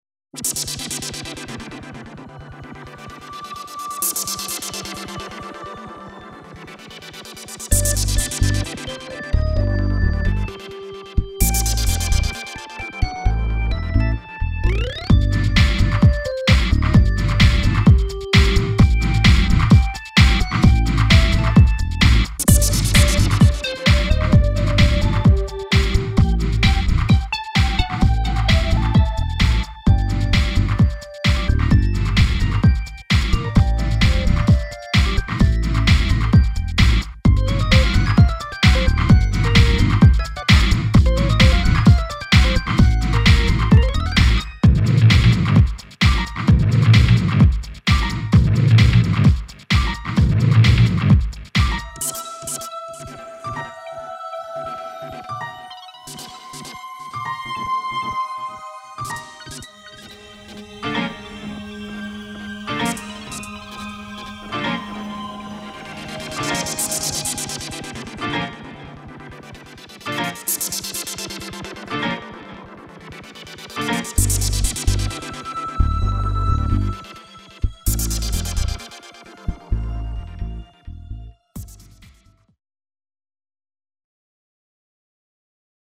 —Synthesizers triggered by guitars
—Guitars played through synths
—Sonics that reverberate from within the earth
—Pulsations to make you move your hips